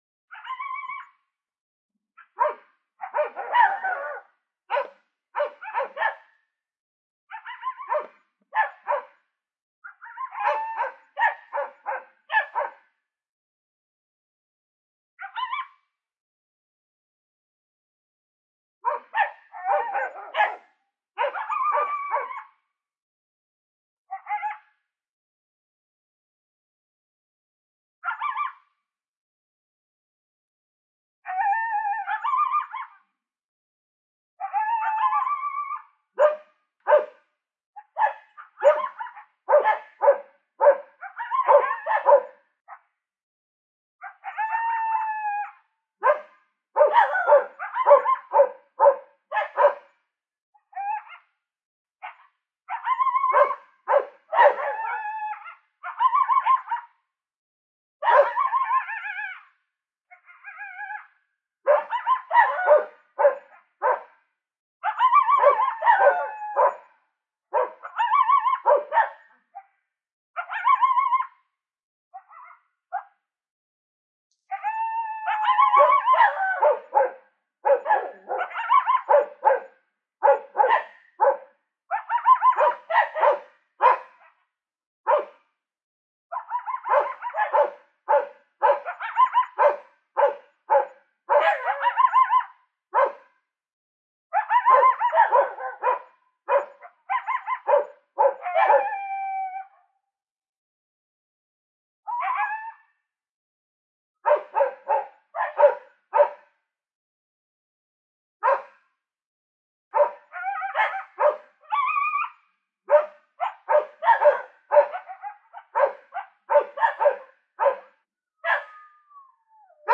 Coyote Dog Fight Fight Fight Bouton sonore